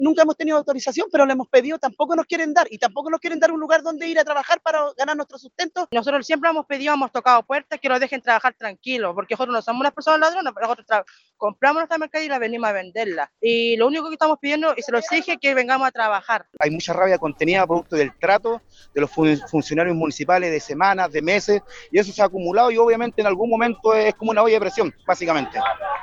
Testimonios recogidos en el lugar revelan el malestar de los comerciantes informales, que acusan años de abandono por parte del municipio y denuncian la negativa sistemática a otorgarles permisos para trabajar legalmente.